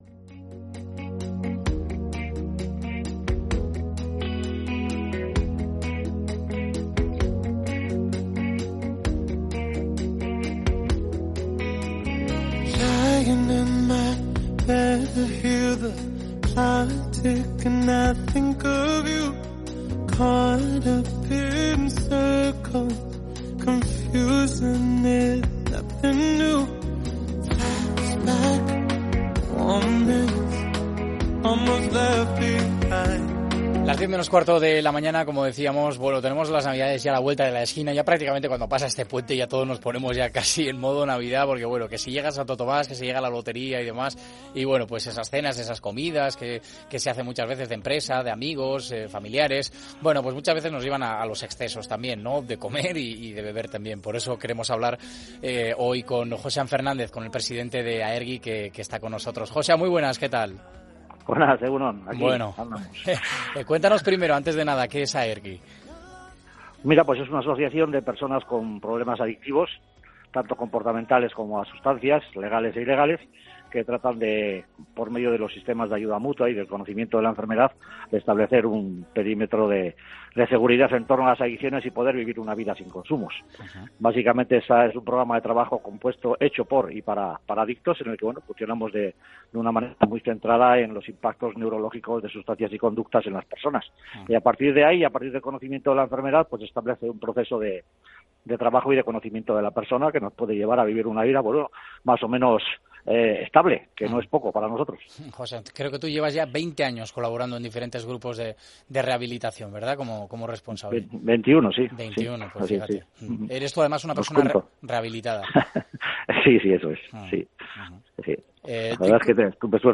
Hemos querido hablar con un experto para que nos de algunos consejos que nos permitan vivir unas fechas con menos excesos, que en poco contribuyen a que lo pasemos mejor.